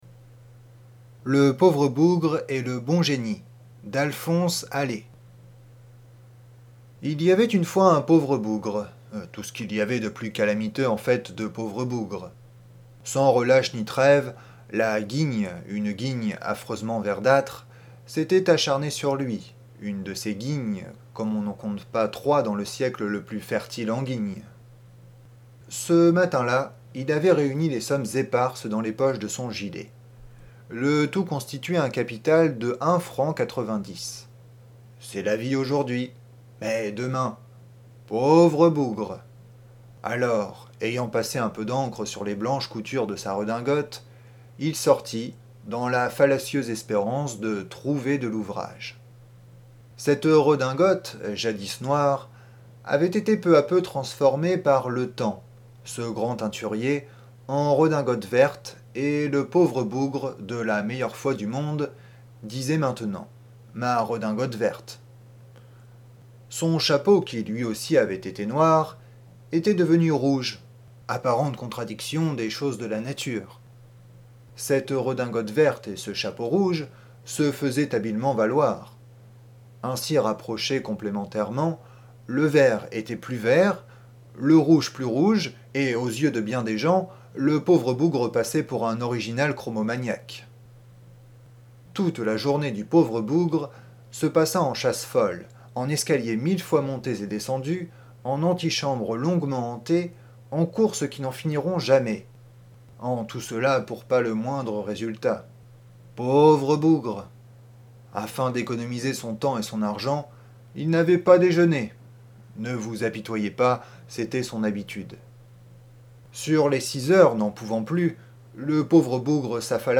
Livre audio gratuit publié le 12 janvier 2011.
Un célèbre monologue d’Alphonse Allais, dont on sent bien la sympathie pour son « pauvre bougre » !